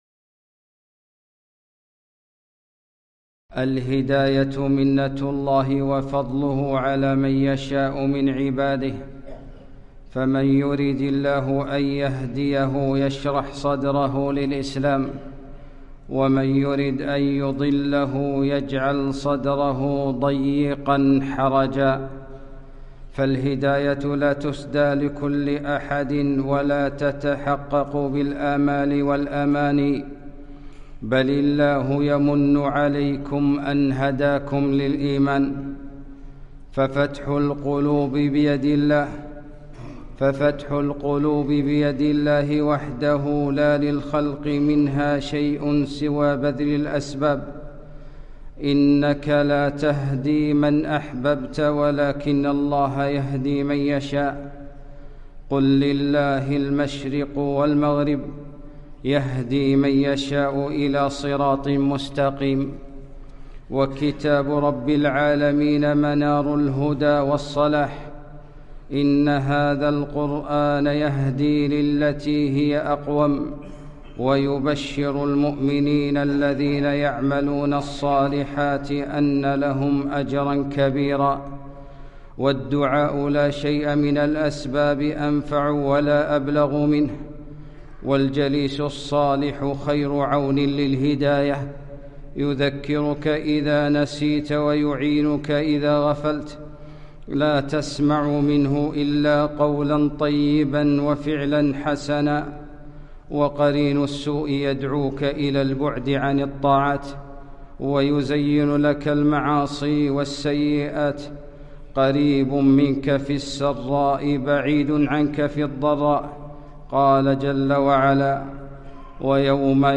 خطبة - إنك لا تهدي من أحببت